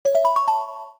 02_Xylophone.ogg